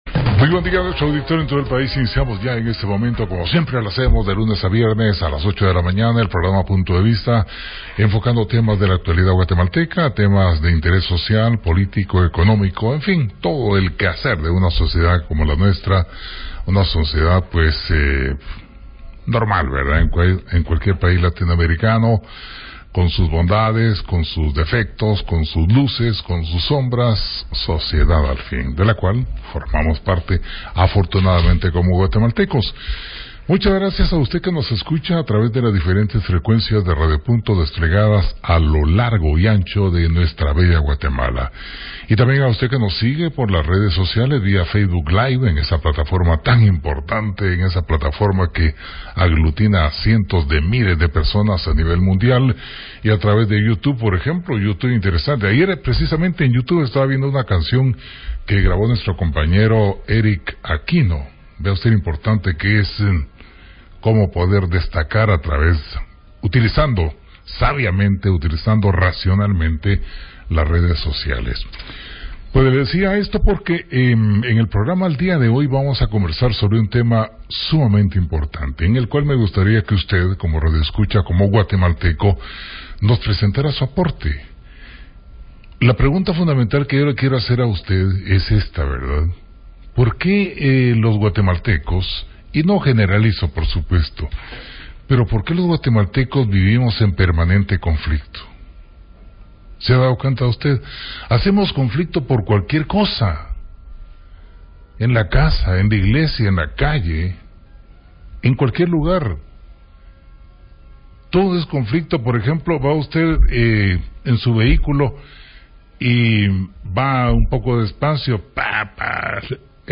PUNTO DE VISTA / RADIO PUNTO: Entrevista